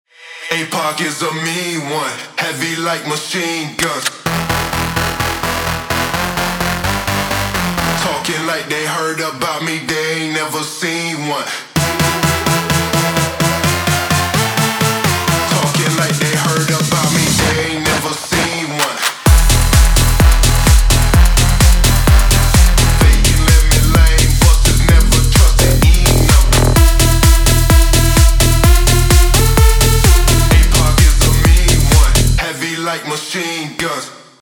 edm
энергичные
техно , melodic techno